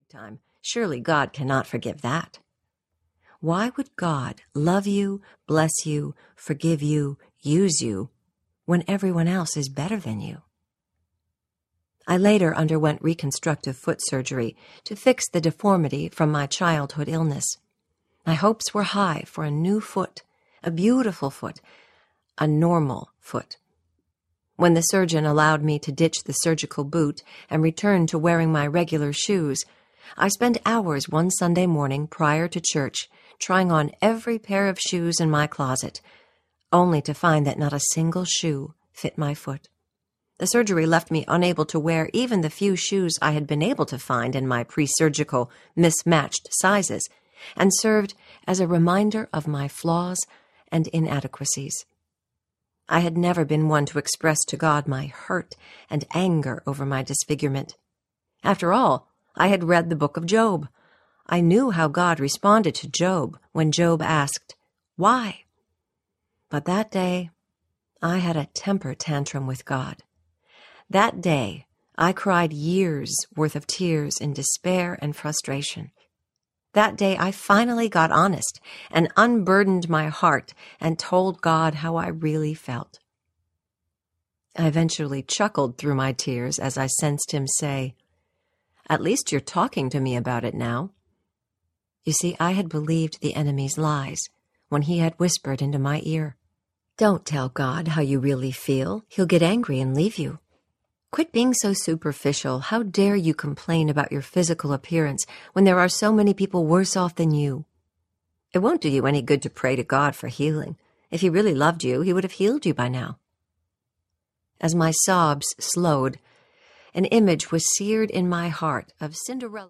Hope Prevails Audiobook
Narrator
6.23 Hrs. – Unabridged